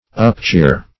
Upcheer \Up*cheer"\
upcheer.mp3